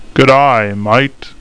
1 channel
hello1.mp3